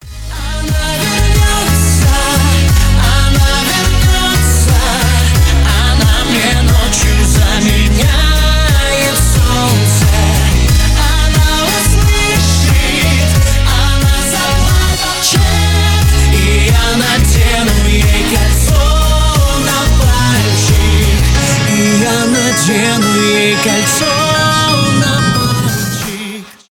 громкие , поп